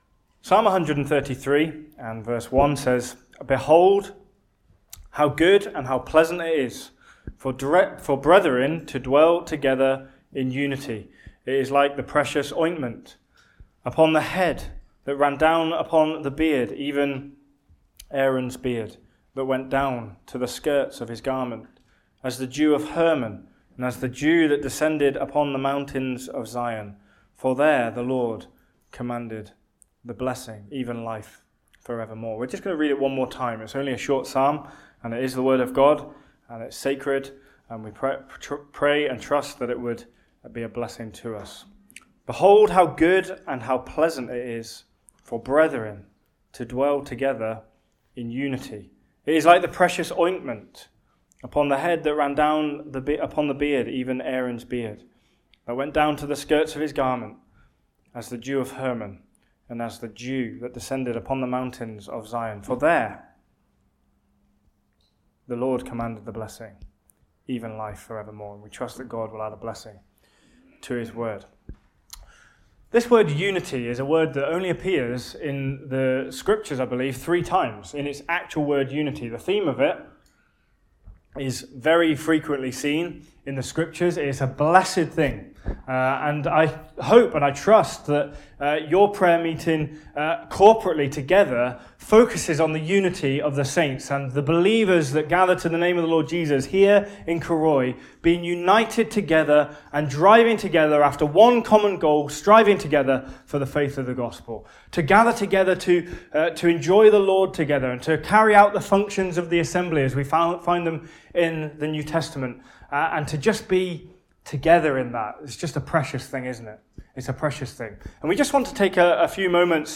Location: Cooroy Gospel Hall (Cooroy, QLD, Australia)